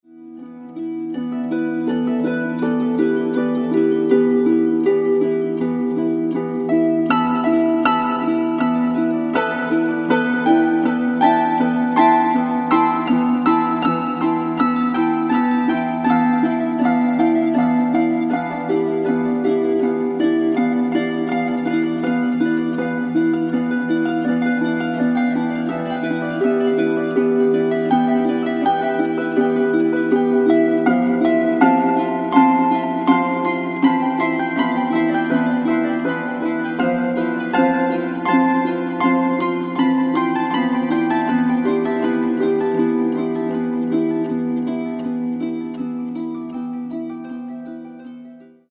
(Celtic harp)  4'594.57 MB1.70 Eur